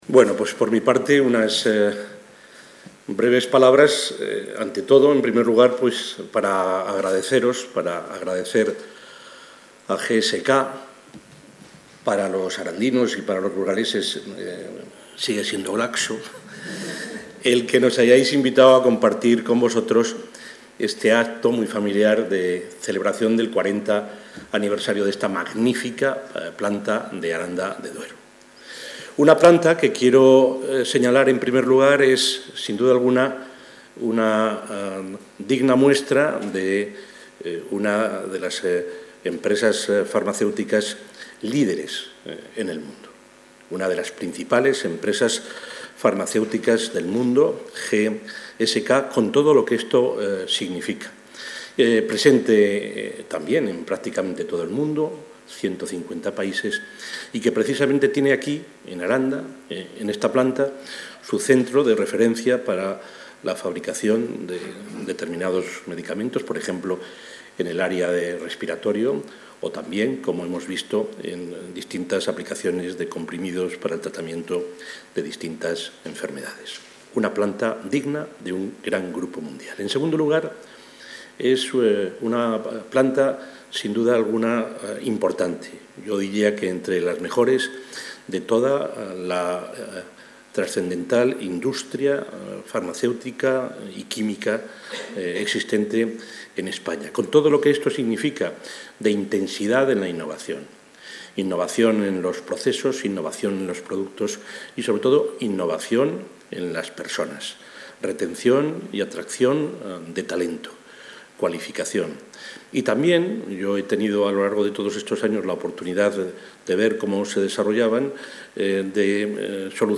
Audio presidente.